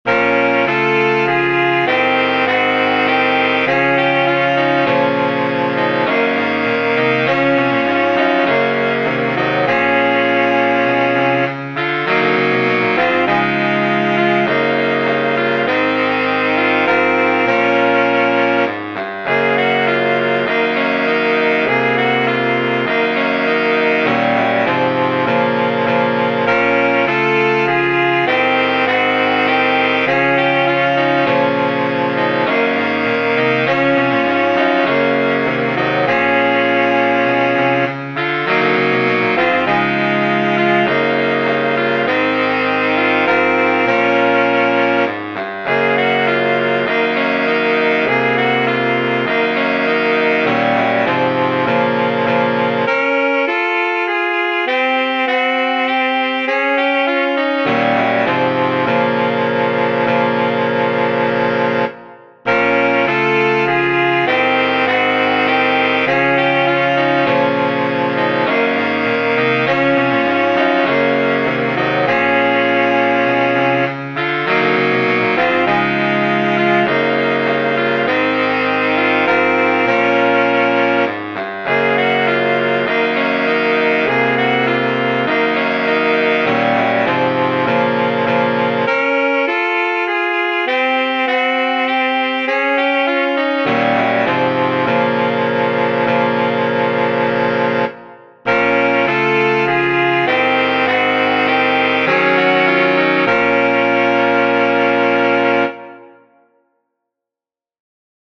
All the hounds of Hell are at our heels  (Saxophone quartet)